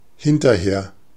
Ääntäminen
Vaihtoehtoiset kirjoitusmuodot (rikkinäinen englanti) arter (rikkinäinen englanti) afther (vanhentunut) aftre (rikkinäinen englanti) aftah Ääntäminen US : IPA : /ˈæf.tɚ/ UK : IPA : /ˈɑːftə(ɹ)/ IPA : /ˈæf.tə(ɹ)/ Tuntematon aksentti: IPA : /ˈɑːf.tə/